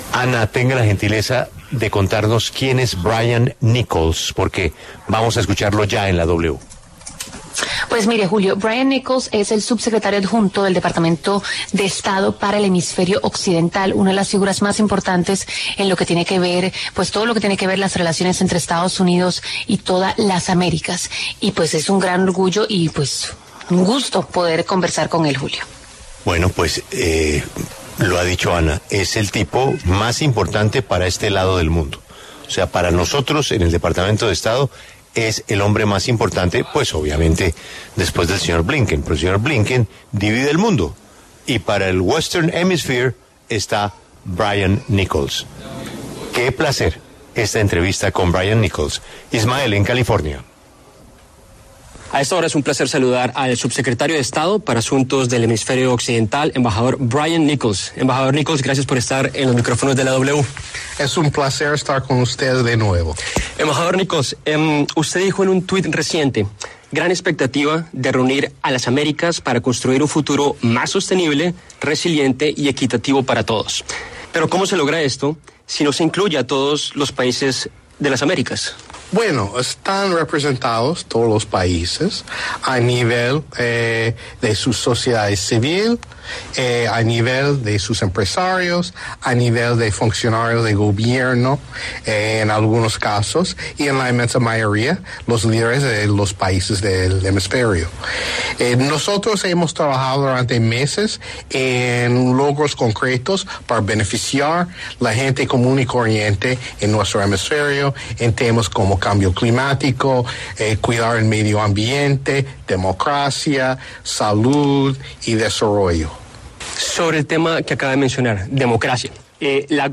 En el marco de la Cumbre de las Américas, La W dialogó con Brian Nichols, subsecretario de Estado para Asuntos del Hemisferio Occidental de Estados Unidos, sobre los grandes ausentes del evento y los principales retos en la región.
En el encabezado escuche la entrevista completa con Brian Nichols, subsecretario de Estado para Asuntos del Hemisferio Occidental de Estados Unidos.